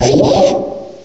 cry_not_accelgor.aif